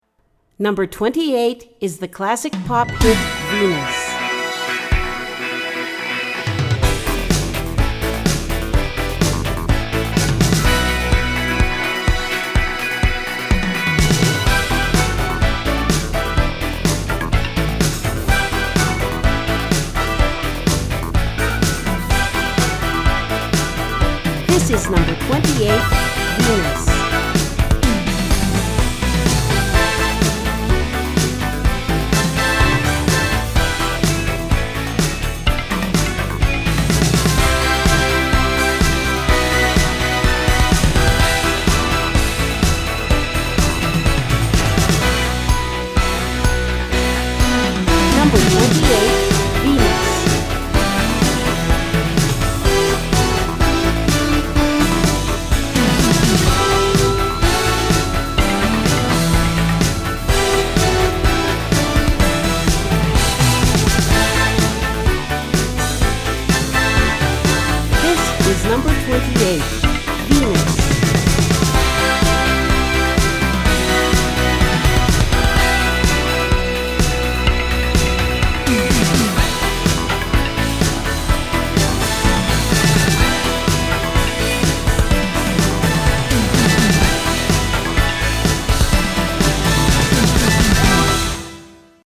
Tag: Classic Pop
Style: 80's